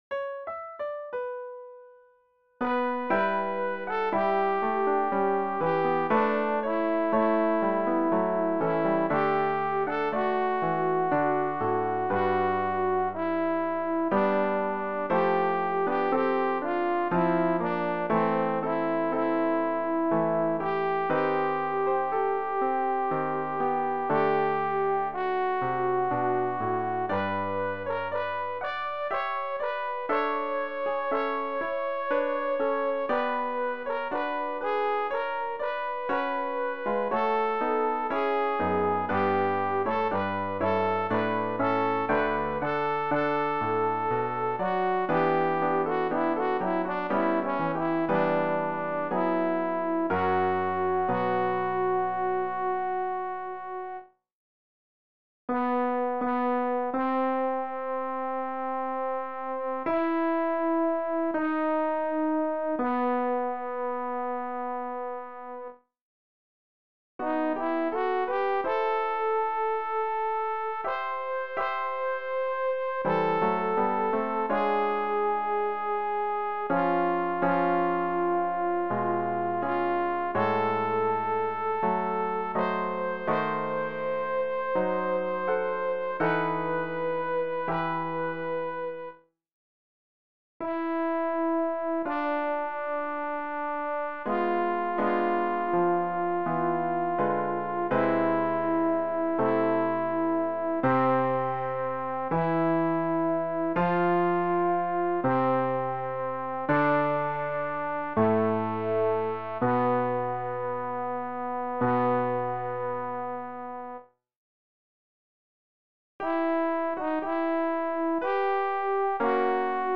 Sopran 2